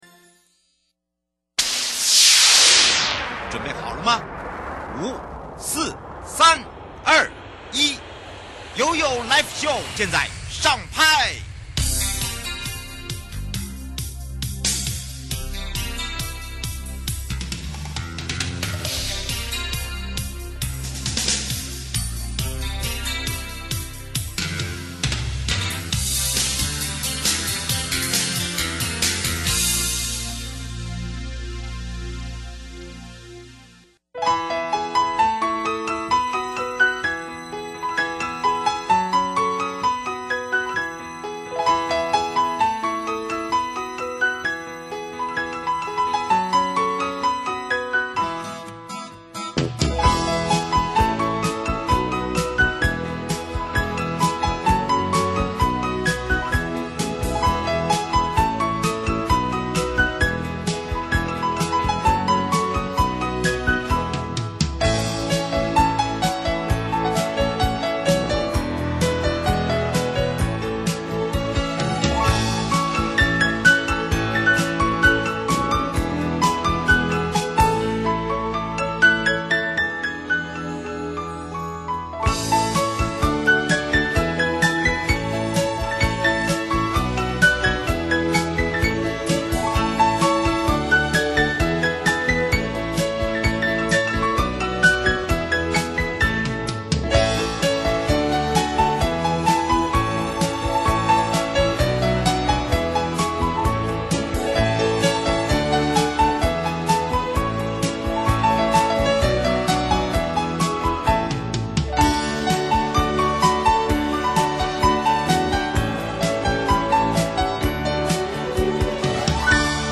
受訪者： 營建你我他 快樂平安行 綠島鄉公所日後對提升道路品質計畫有何期許?對於前瞻提升道路品質計畫推動感想如何?計畫完成後針對日後維護管理之經費運用?(下集) 節目內容： 綠島鄉公所謝賢裕鄉長